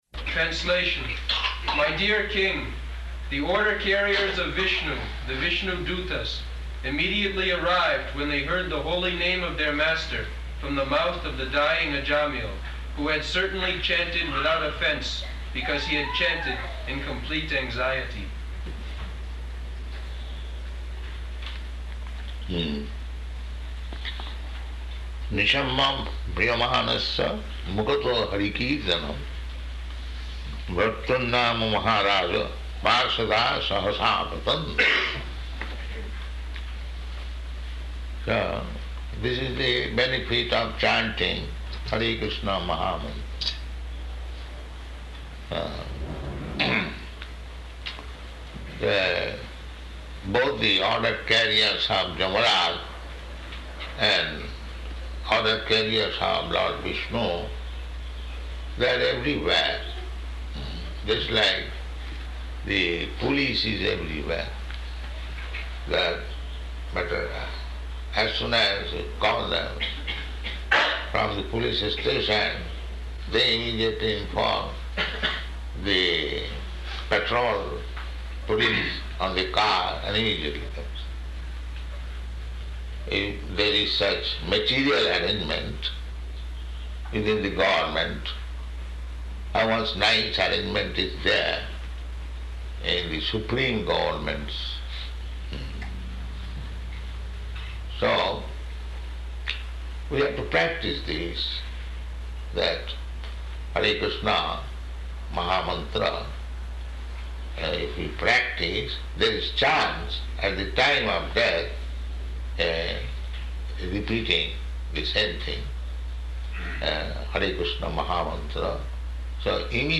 Type: Srimad-Bhagavatam
Location: Honolulu